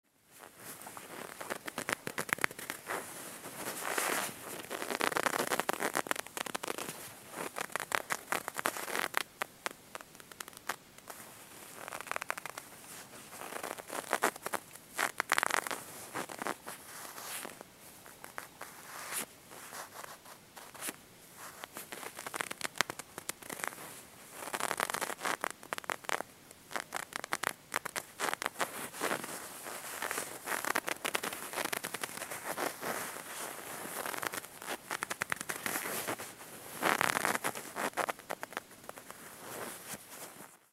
Звуки ваты
Хрустящие, шуршащие и мягкие аудиофайлы помогут расслабиться, создать атмосферу или использовать их в своих проектах.
Звук сжатия кусочка ваты между пальцами не всем приятен